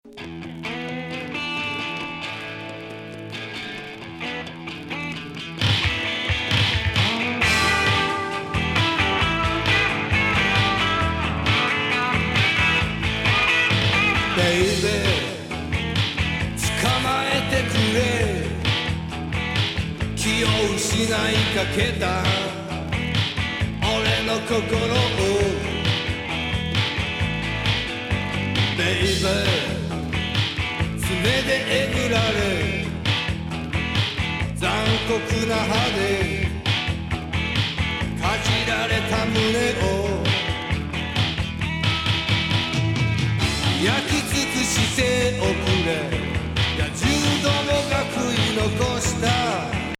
アヴァロン的 ロッキン・バレアリック